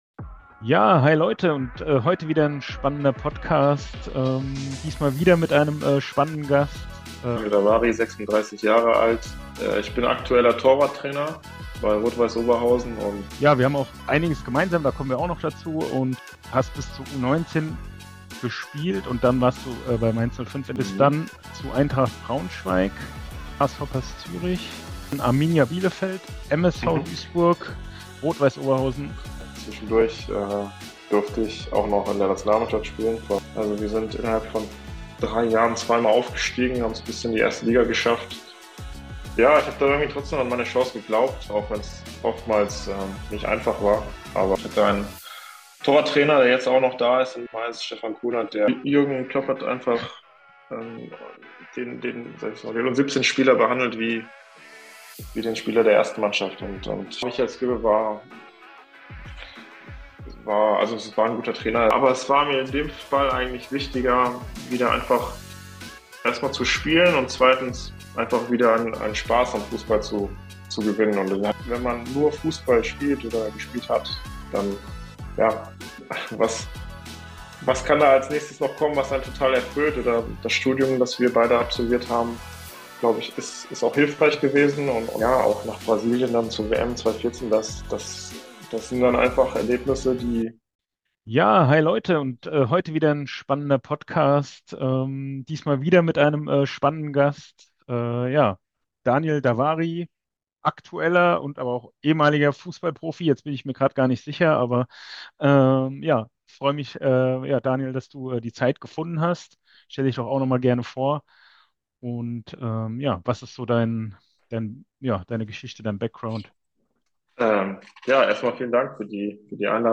Beschreibung vor 1 Jahr Diesmal hatte ich die Möglichkeit Daniel Davari, einen ehemaligen Jugend- und Seniorenspieler von Mainz 05, Profifußballer von Eintracht Braunschweig, Grashoppers Zürich, Arminia Bielefeld, MSV Duisburg, Rot Weiss Oberhausen, Rot Weiss Essen und heute Torwarttrainer bei RWO zu interviewen . Wir haben über seinen Weg zum Profi Fussballer, welche Hürden er überwinden musste, welche Qualitäten ein Torwart haben muss, um erfolgreich zu sein, die Nationalmannschaft , die WM in Brasilien , Dubai Schokolade und vieles mehr gesprochen.